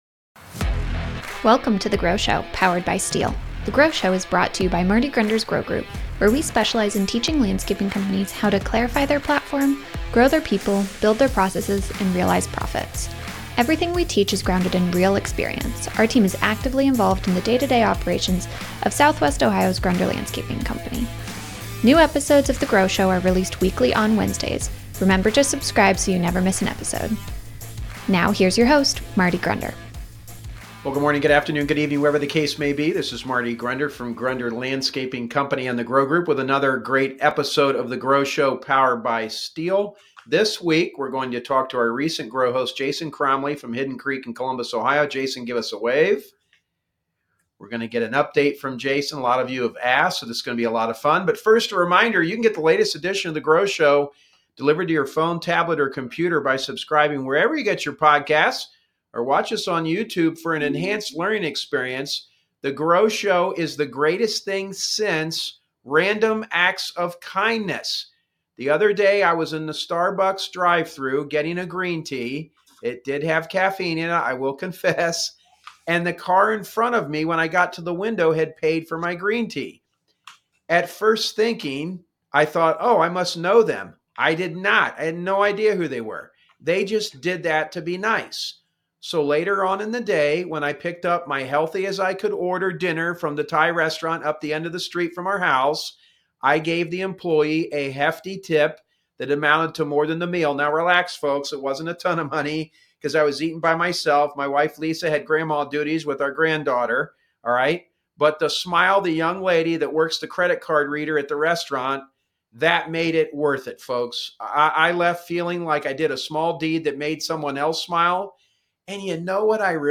Interview Series